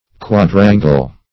Quadrangle \Quad"ran`gle\, n. [F., fr. L. quadrangulum; quattuor